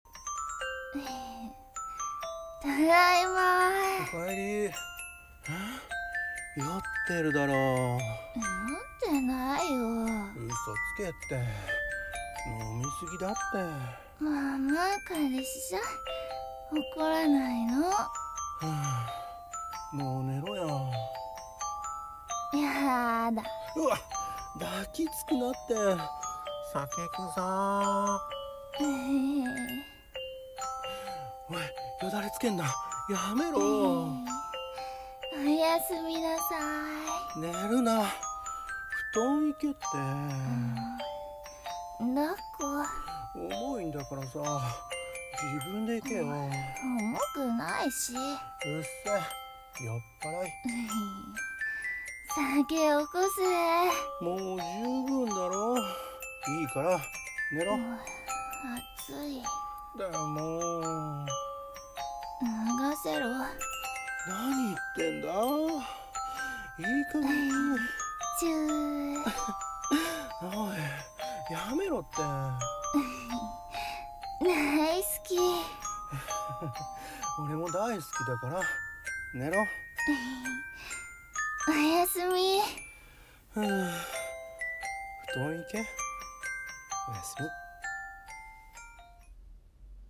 【声劇】酔っ払い彼女